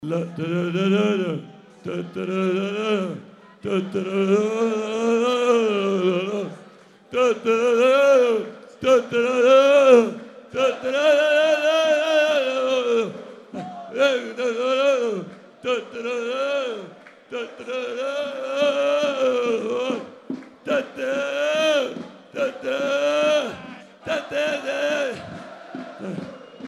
De kampioenenmaker nam het woord tijdens de viering en ging helemaal uit de bol. Voor de Genk-fans hebben we zijn nu al legendarische gezangen als beltoon afgezonderd.
Bekijk hier nog eens de beelden van een zingende Clement